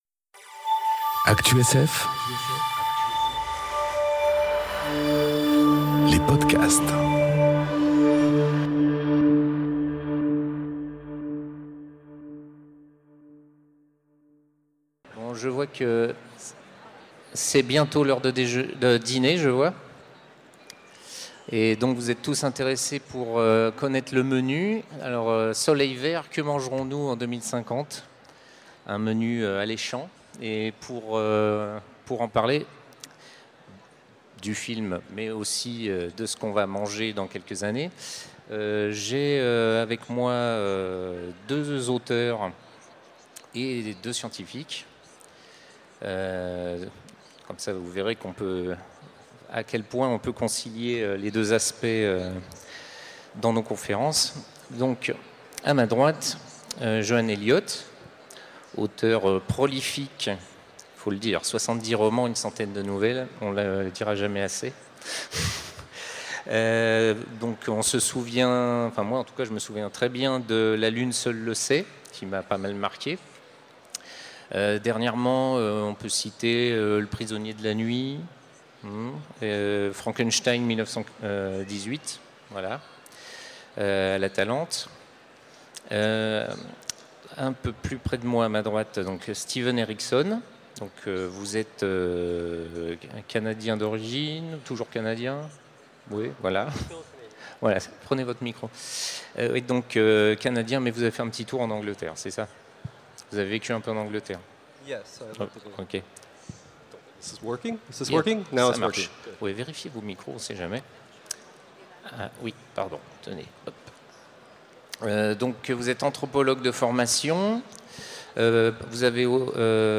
Conférence Soleil Vert, que mangerons-nous demain ? enregistrée aux Utopiales 2018